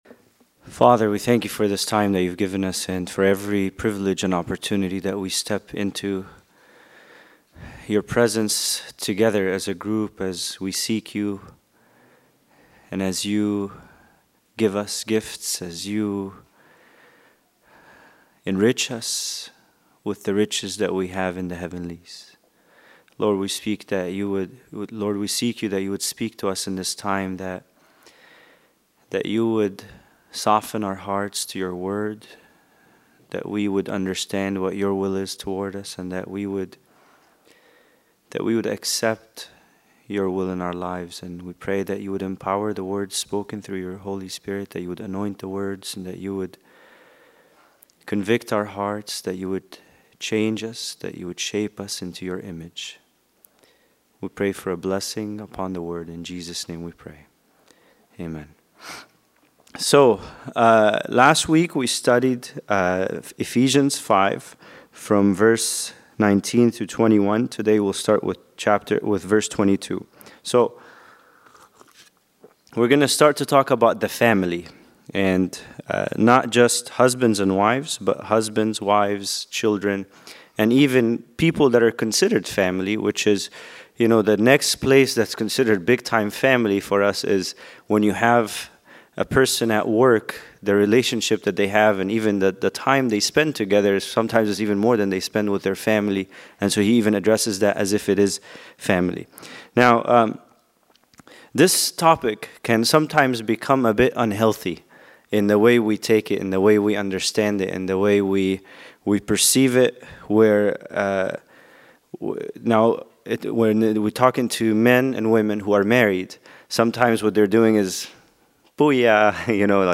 Bible Study: Ephesians 5:22-24